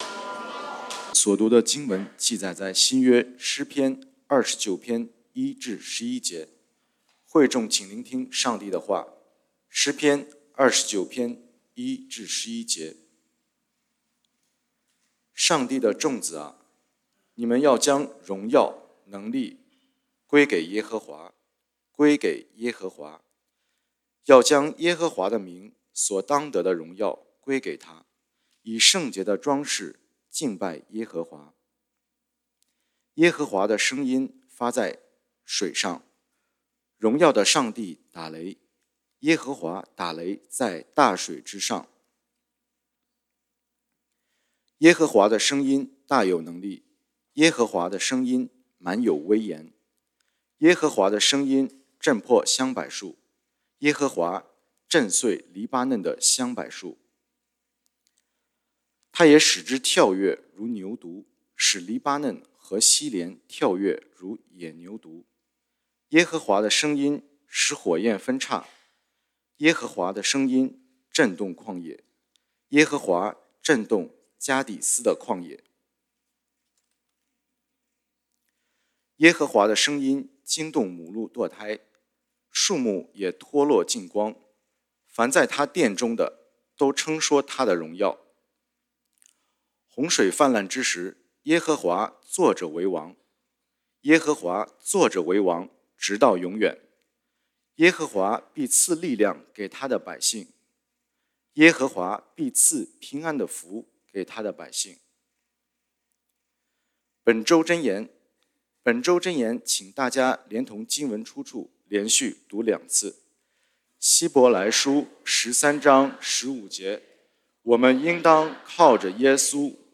感恩見證